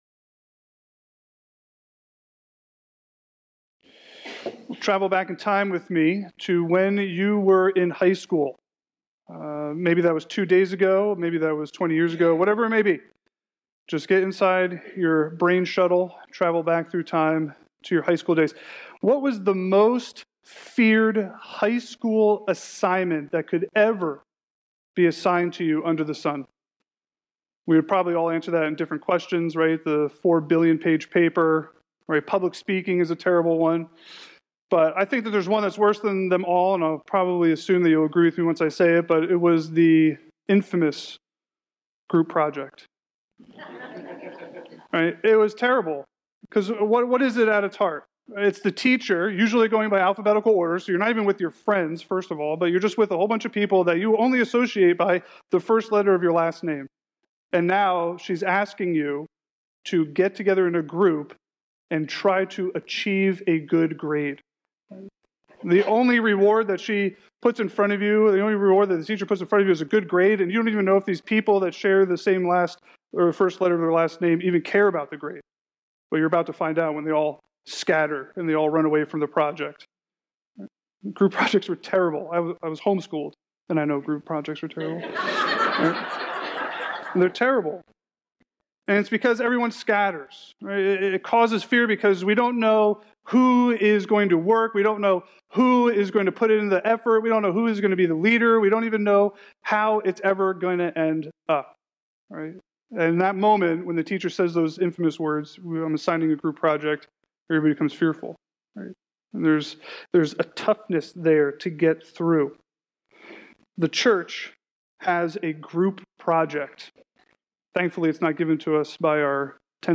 Expositional preaching series through the gospel of John - so that you may believe!